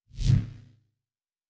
pgs/Assets/Audio/Sci-Fi Sounds/Weapons/Lightsaber 2_2.wav at master
Lightsaber 2_2.wav